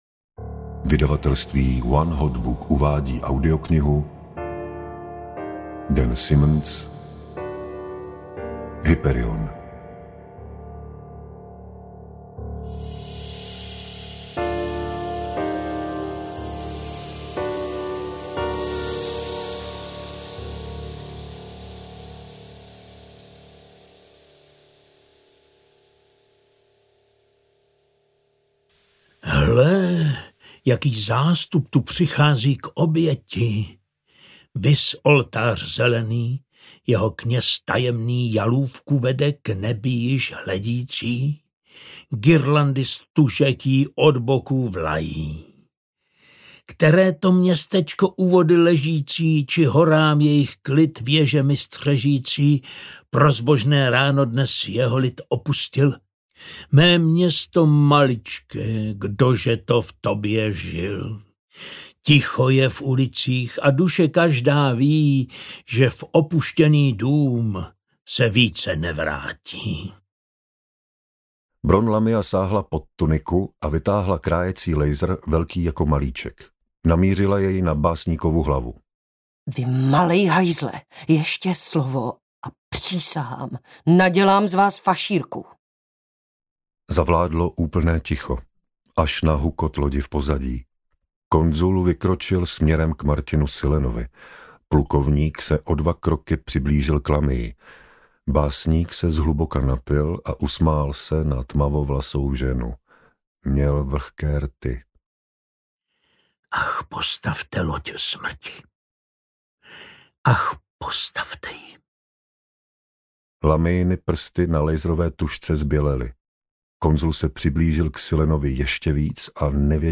Hyperion audiokniha
Ukázka z knihy